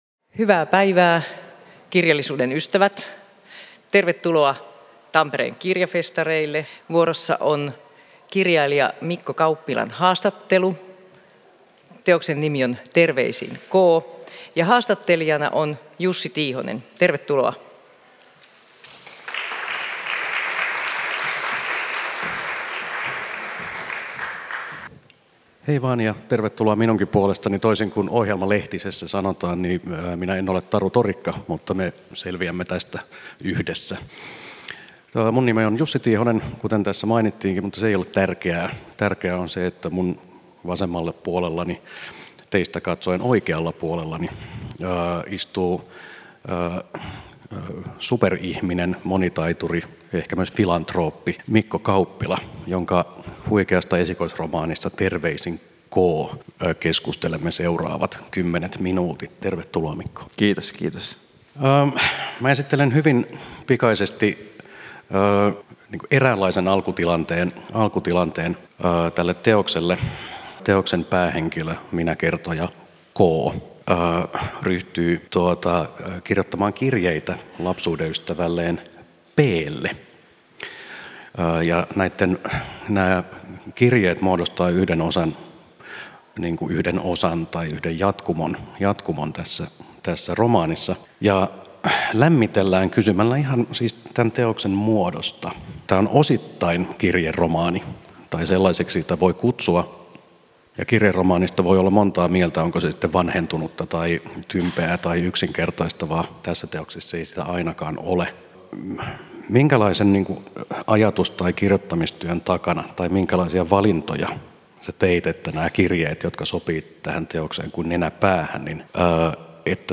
Ohjelma on taltioitu Tampereen Kirjafestareilla 2024. https